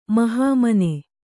♪ mahāmane